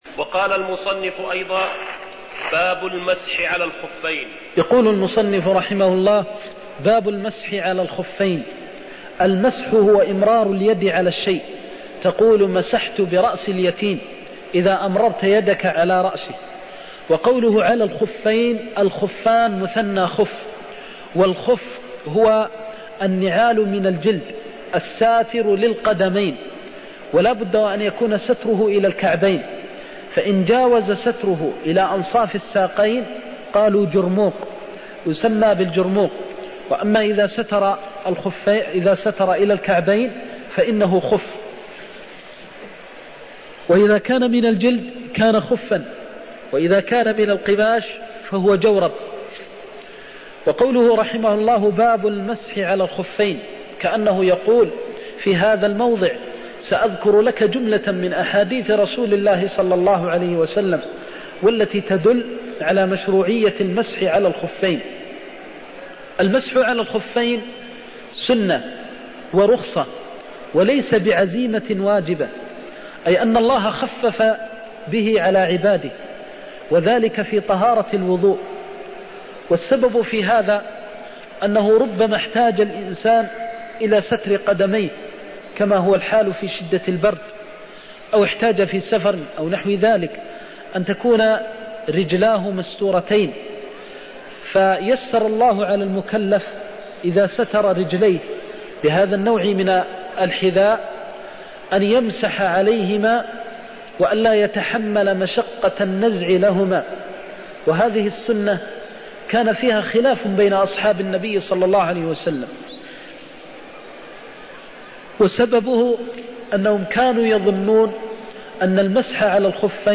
المكان: المسجد النبوي الشيخ: فضيلة الشيخ د. محمد بن محمد المختار فضيلة الشيخ د. محمد بن محمد المختار دعهما فإني أدخلتهما طاهرتين (22)